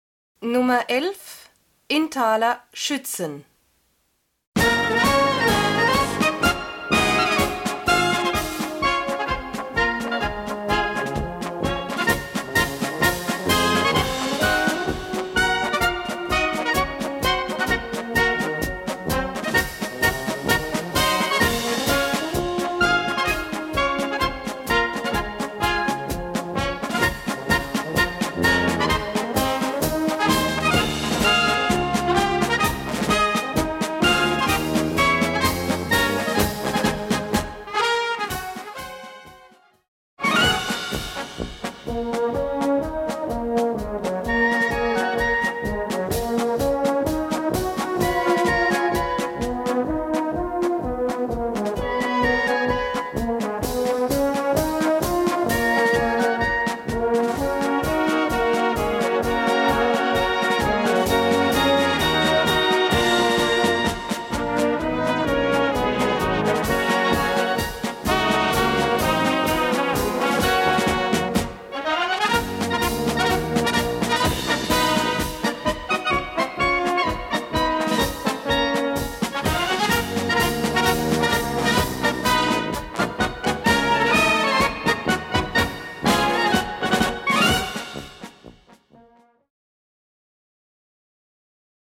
Gattung: Marsch
B Besetzung: Blasorchester PDF